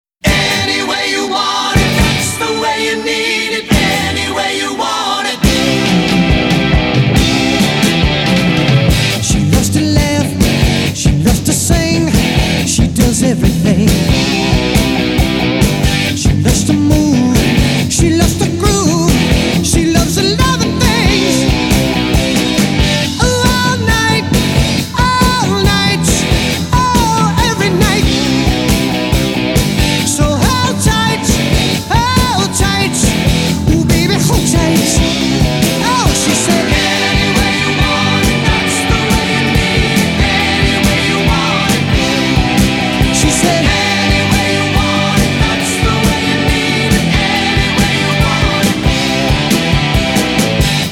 • Качество: 192, Stereo
поп
мужской вокал
легкий рок
танцевальные
РОК-Н-РОЛЛ